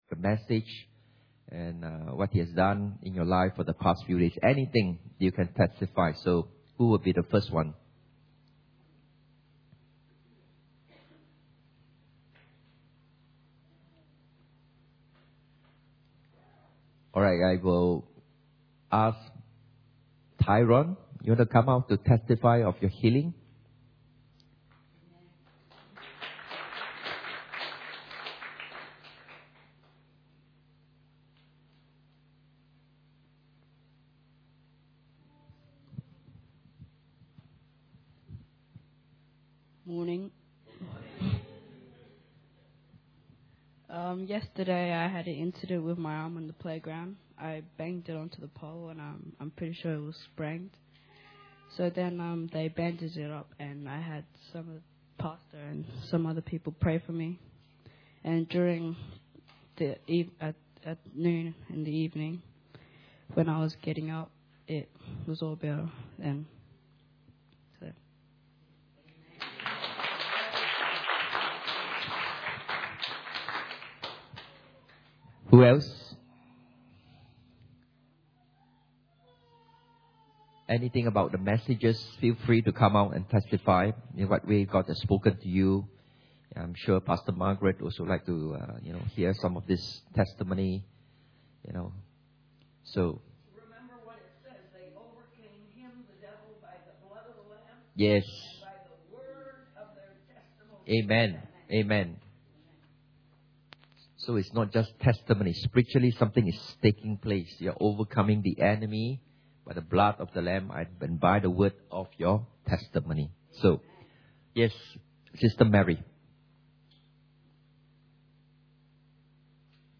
Church Camp 2012 Session 8 – Testimonies
Series: Church Camp 2012